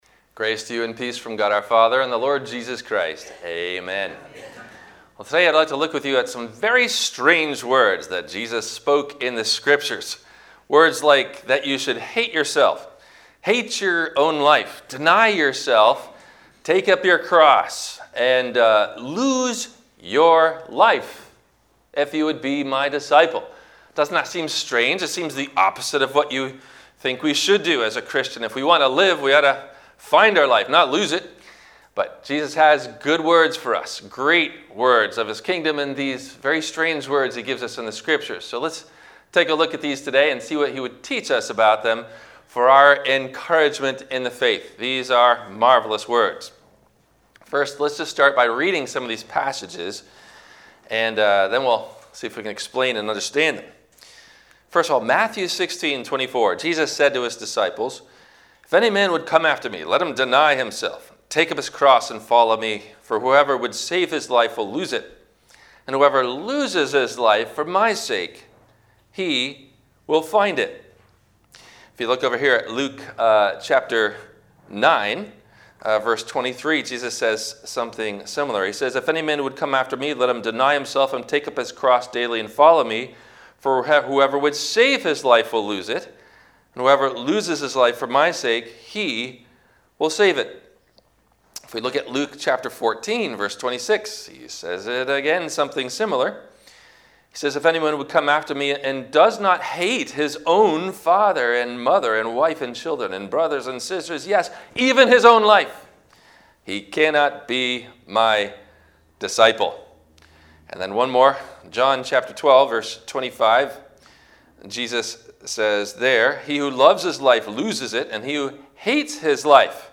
- Sermon - December 06 2020 - Christ Lutheran Cape Canaveral